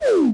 Media:primo_super_01.wav 技能音效 super 使出飞身肘击音效